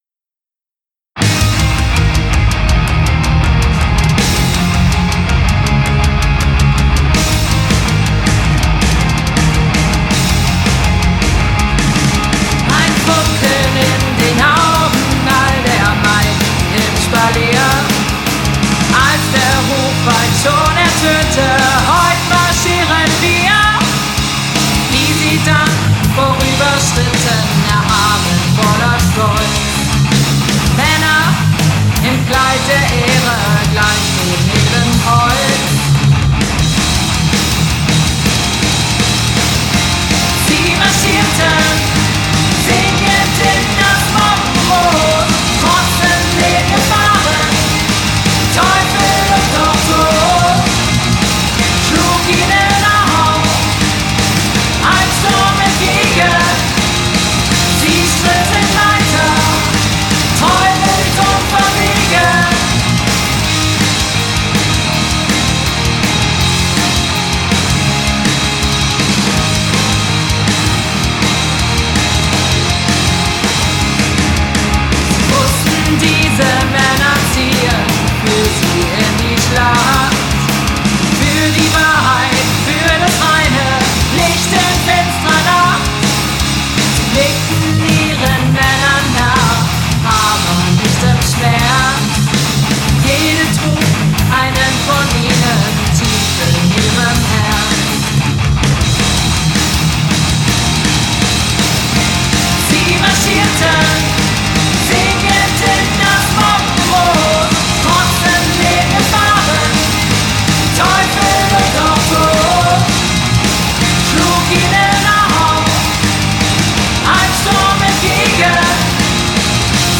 gekonnten Damengesang
überwiegend rockigen Nummern